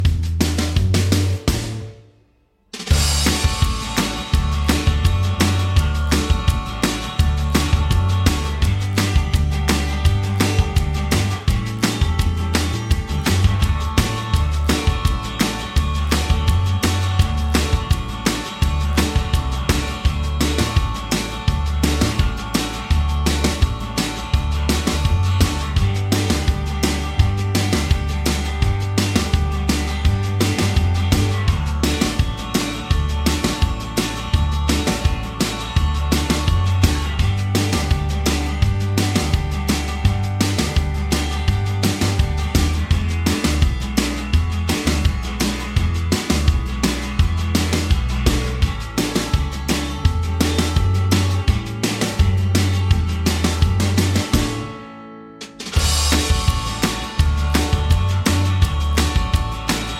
Minus Main Guitar For Guitarists 3:52 Buy £1.50